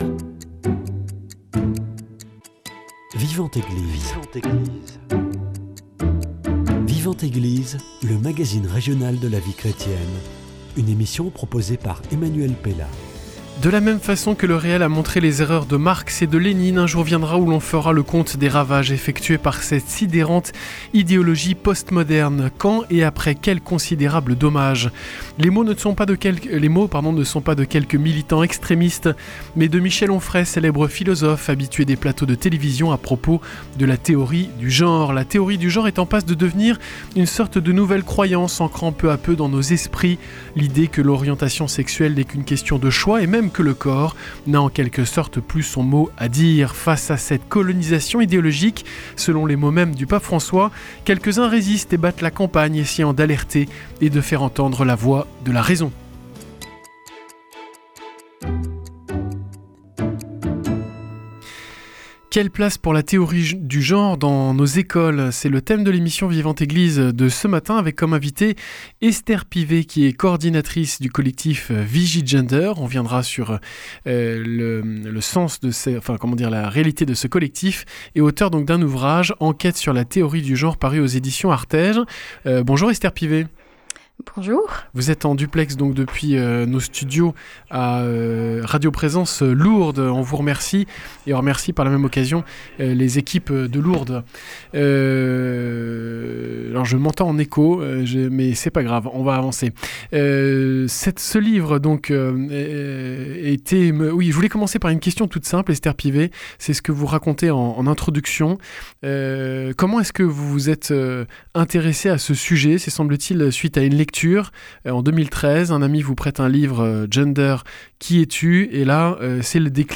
Voilà, pour résumer de manière rapide, le propos de mon invité de ce matin. Cette théorie qui postule que la sexualité est un choix qui ne dépend pas des données biologiques a en quelques années gagnées tous les esprits, et s’est bel et bien introduit, quoi qu’en ait dit Najat Vallaud-Belkacem, ancien ministre de l’Éducation nationale, dans les écoles de nos enfants.